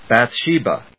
音節Bath・she・ba 発音記号・読み方
/bæθʃíːbə(米国英語), bæˈθʃi:bʌ(英国英語)/